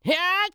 CK蓄力02.wav
人声采集素材/男2刺客型/CK蓄力02.wav